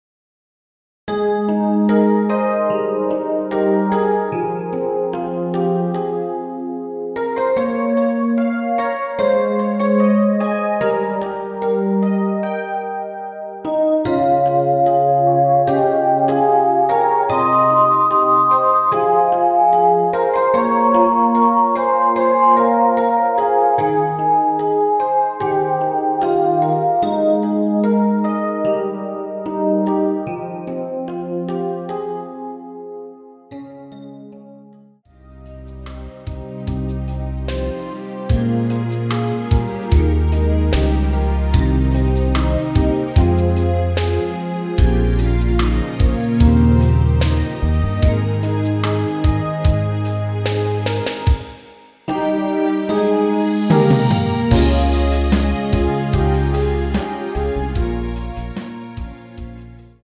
원키에서(+3)올린 MR입니다.
Ab
◈ 곡명 옆 (-1)은 반음 내림, (+1)은 반음 올림 입니다.
앞부분30초, 뒷부분30초씩 편집해서 올려 드리고 있습니다.